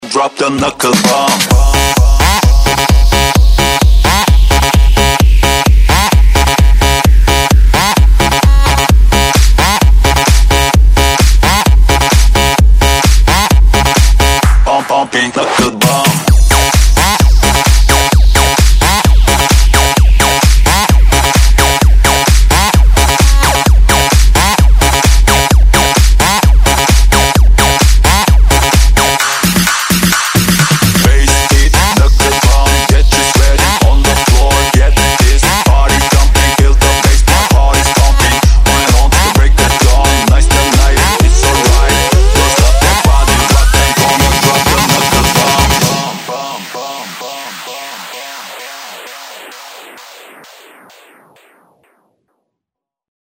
• Качество: 128, Stereo
новый заводной трек